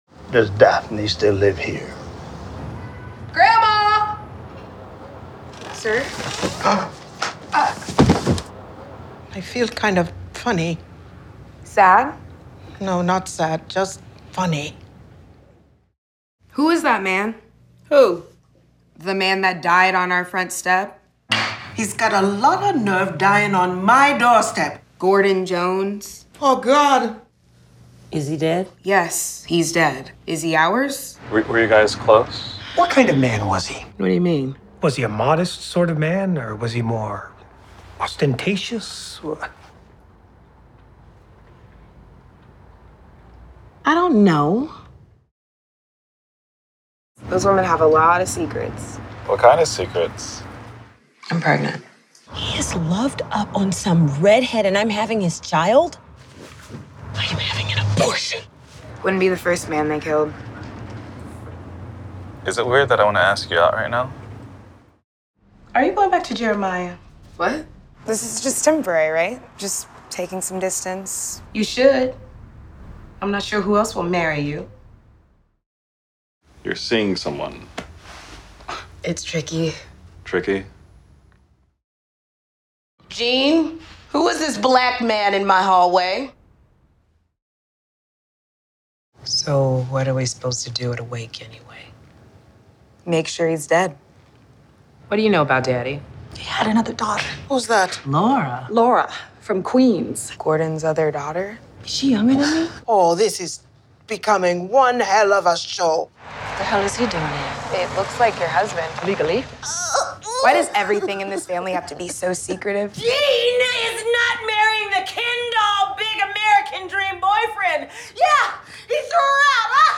jeanne of the jonese_tca_cut 14 LONG VERSION_rev1_SOT.wav